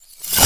casting_charge_matter_fast_02.wav